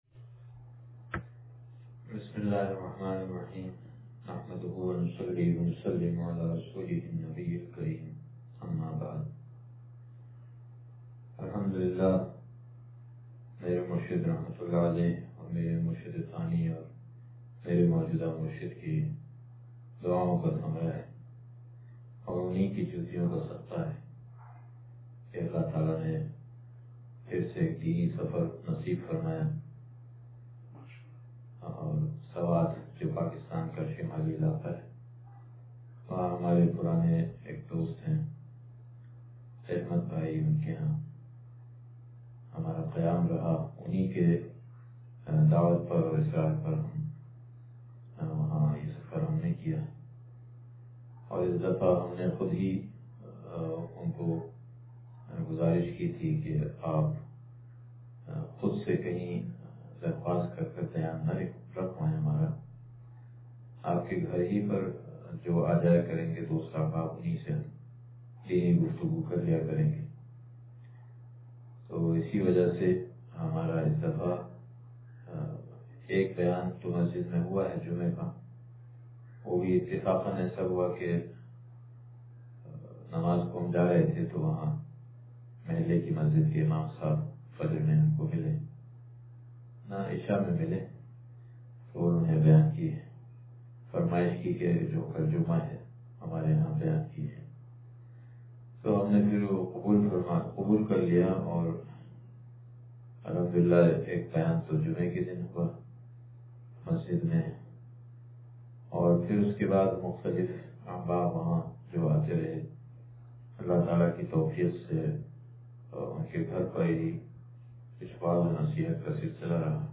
سفرِ سوات و اسلام آباد سے واپسی پر بیان – دنیا کی حقیقت – نشر الطیب فی ذکر النبی الحبیب صلی اللہ علیہ وسلم